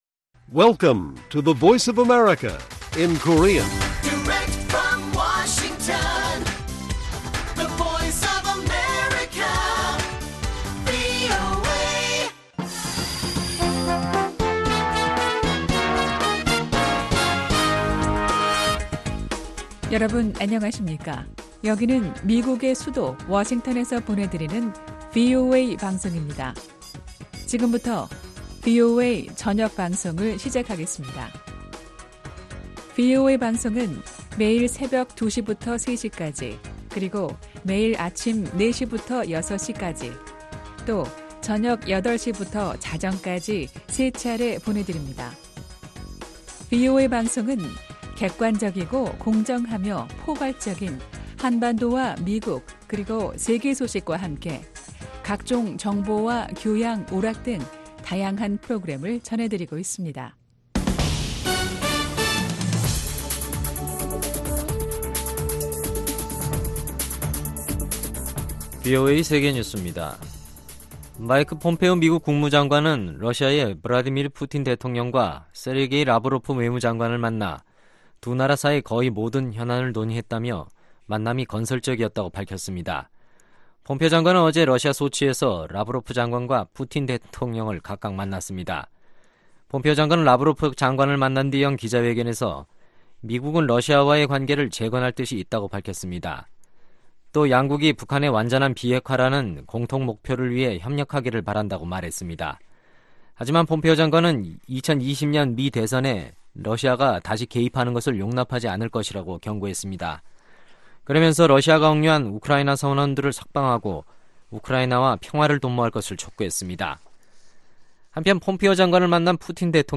VOA 한국어 간판 뉴스 프로그램 '뉴스 투데이', 2019년 5월 15일 1부 방송입니다. 마이크 폼페오 미 국무장관은 세르게이 라브로프 러시아 외무장관과 만나 대북 제재의 철저한 이행을 강조했습니다. 미국의 미사일 전문가들은 북한이 어느 지점에서 단거리 탄도 미사일을 발사하는지에 따라 한국의 미사일 방어 역량이 취약해질 수 있다고 지적했습니다.